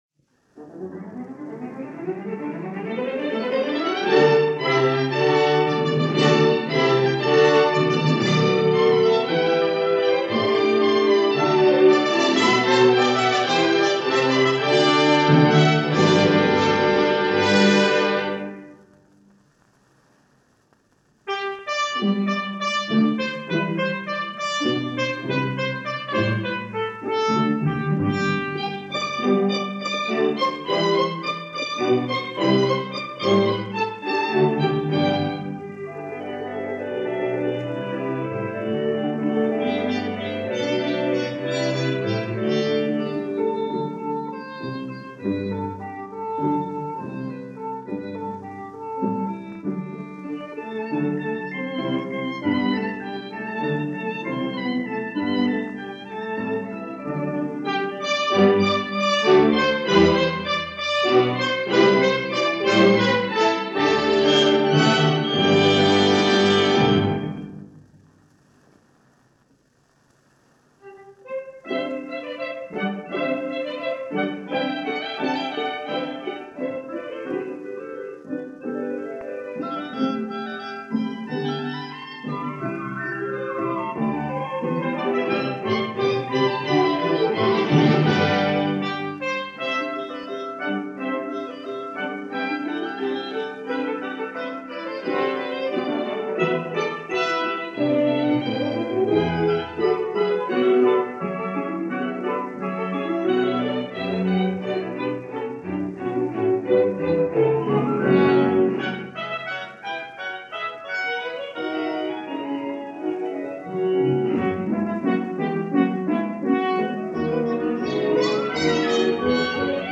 imaginative and highly expressive.